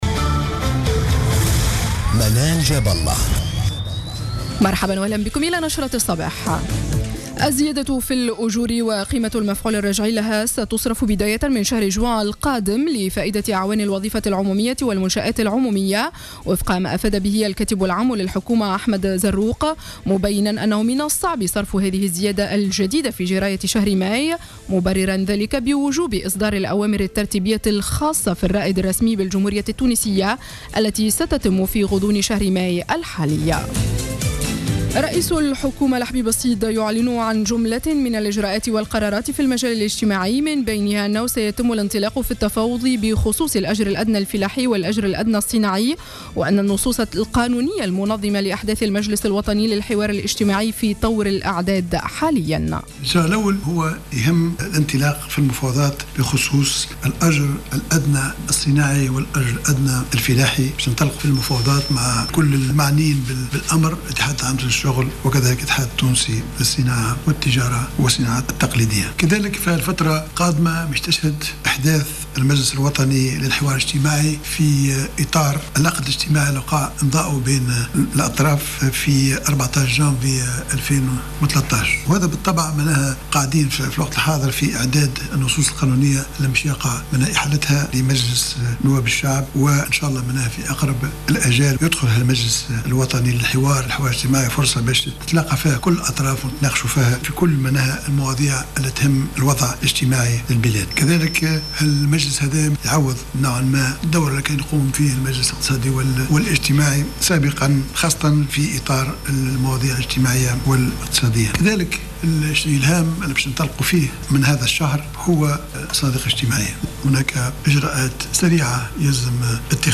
نشرة أخبار السابعة صباحا ليوم السبت 02 ماي 2015